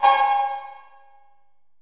808MP56COW.wav